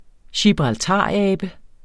Udtale [ ɕibʁɑlˈtɑˀ- ]